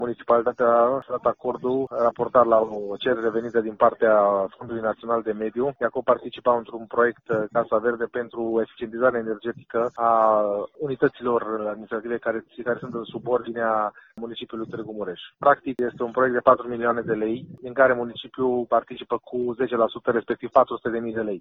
Președintele ședinței Consiliului Local Municipal de ieri, consilierul local Sergiu Papuc: